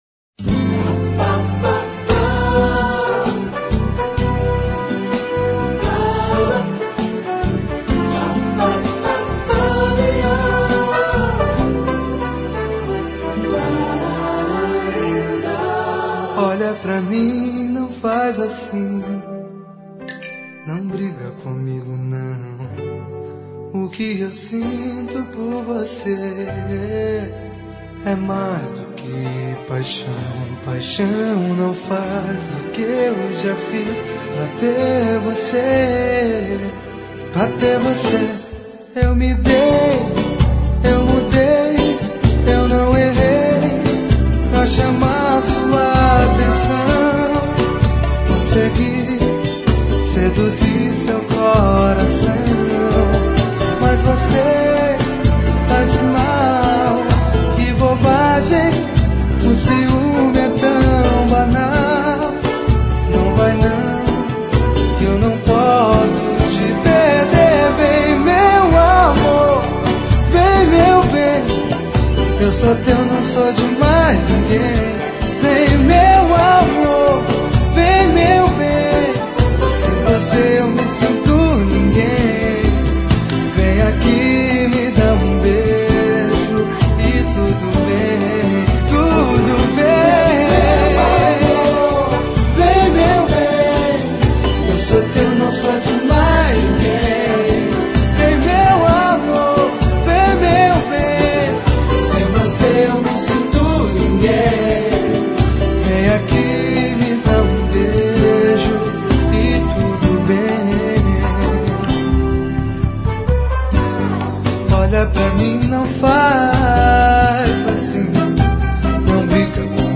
Sambas